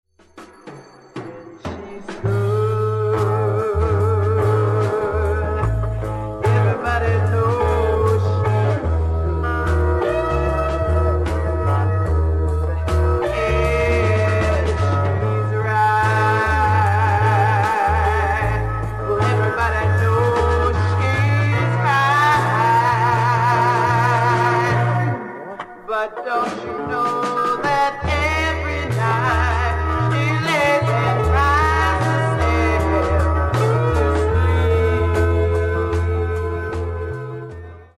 SOFT ROCK / PSYCHEDELIC POP